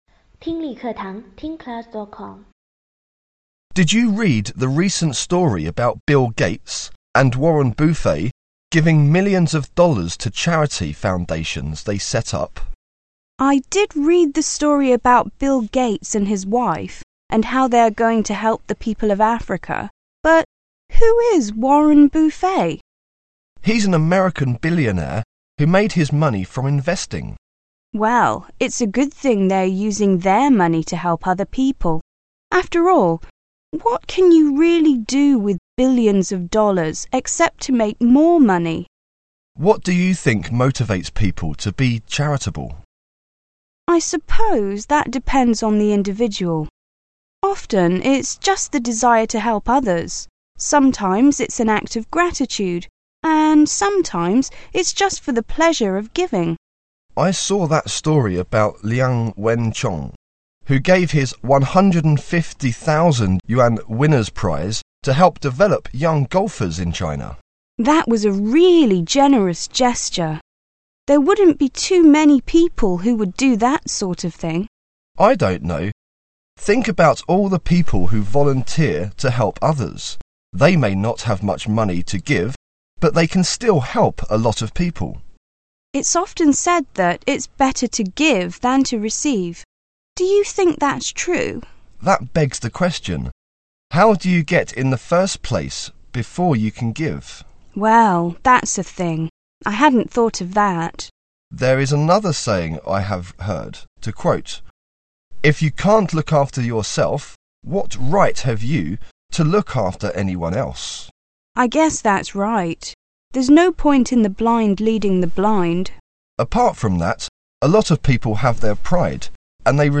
谈论慈善和志愿者英语对话-锐意英语口语资料库12-2